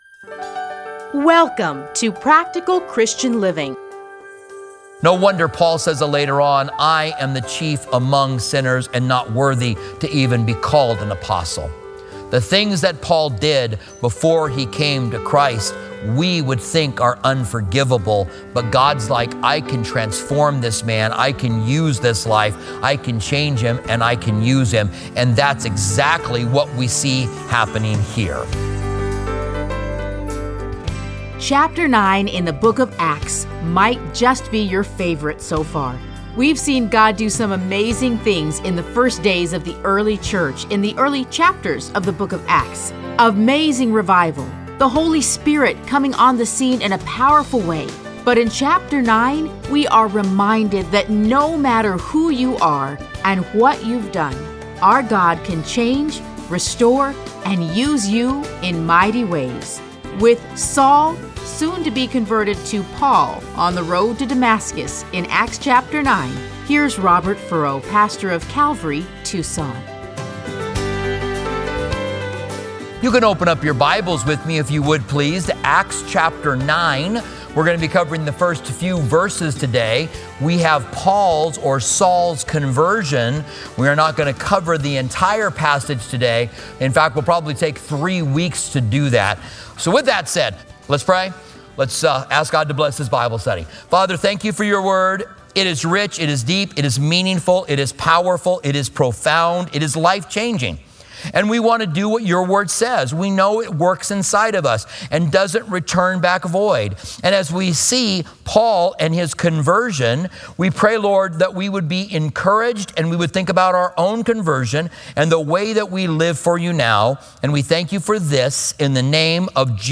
Listen to a teaching from Acts 9:1-9.